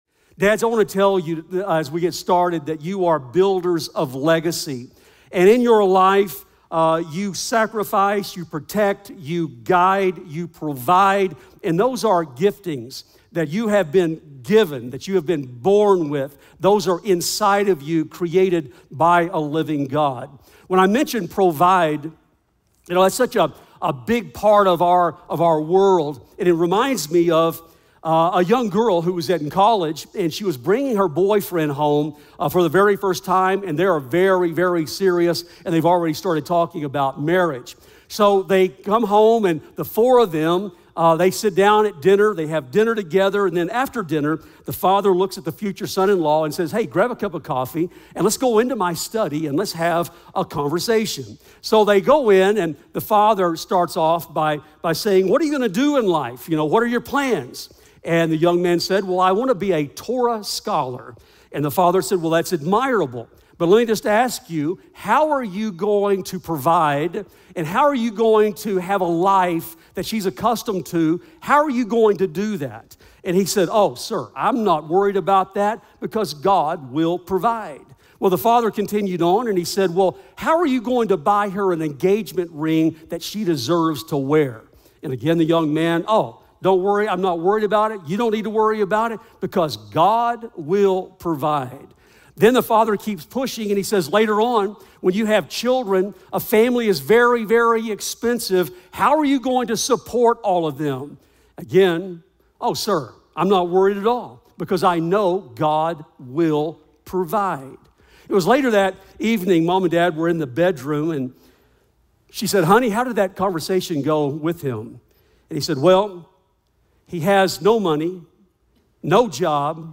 This enriching sermon offers practical advice and spiritual insights for fathers and future fathers.